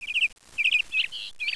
bird1.wav